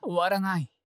戦闘 ダメージ ボイス 声素材 – Damage Voice